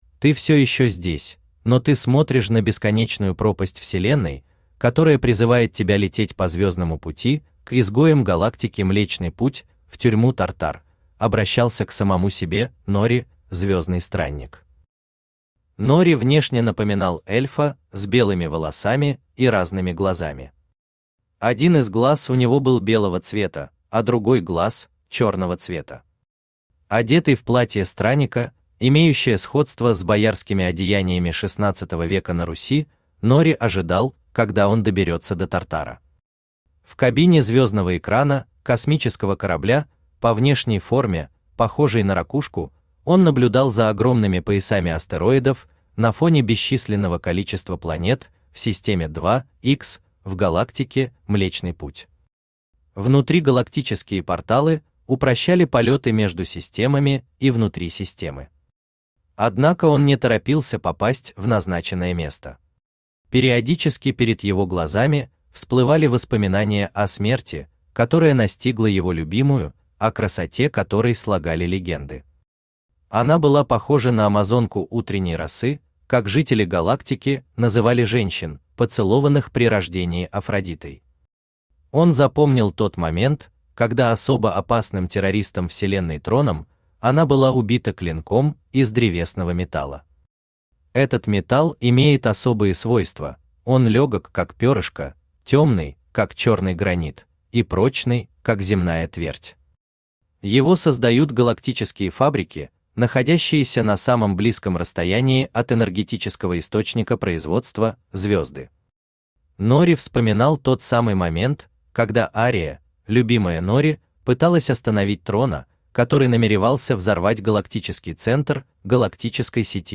Аудиокнига Нори